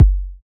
SOUTHSIDE_kick_cleanly.wav